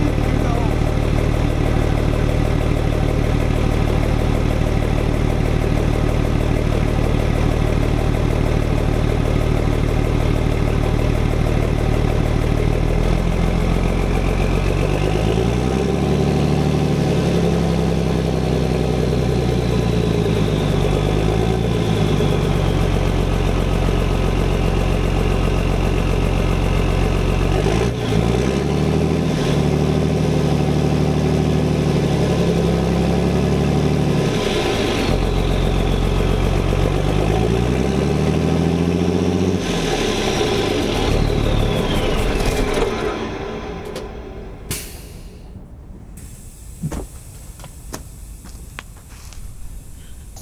12m18: Soundcheck ohne Auspuff
ohne Auspuff klingt der Wagen gleich noch ein bißchen Rallyemäßiger. :woohoo:
ich meine beim 2ten und 5ten Zylinder ein deutlich zu großes Ventilspiel zu hören.